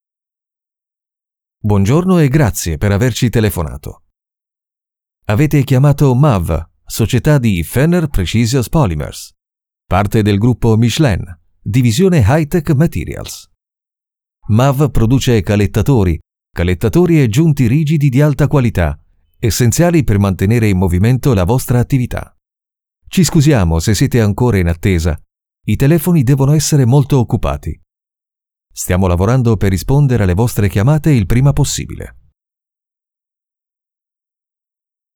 Naturelle, Polyvalente, Fiable, Mature, Douce
Téléphonie